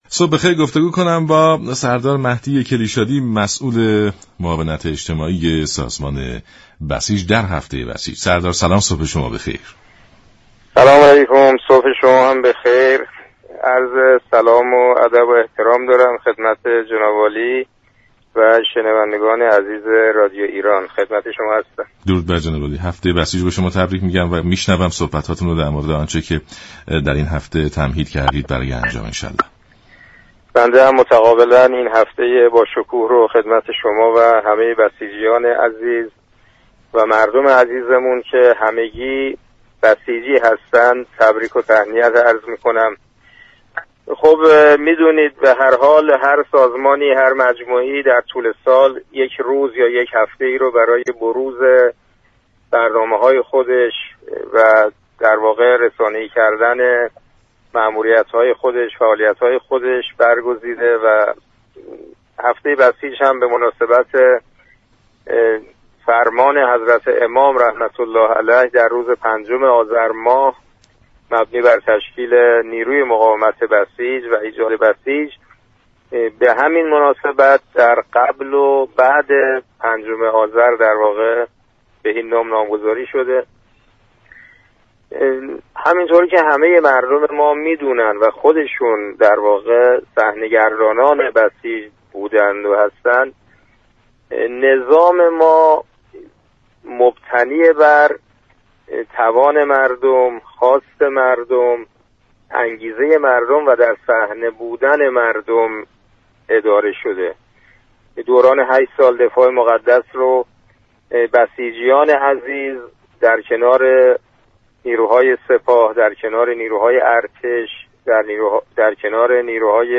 معاون اجتماعی سازمان بسیج در گفت و گو با رادیو ایران گفت: عرصه های مختلف كشور نظیر كشاورزی، بهداشت و درمان، علمی، صنعتی، حقوقی، تعلیم و تربیت، بازار و اصناف و ... بسیج فعالیت دارد